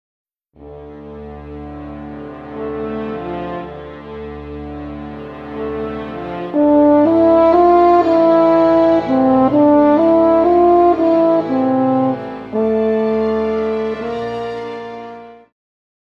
French Horn
Band
Instrumental
World Music,Electronic Music
Only backing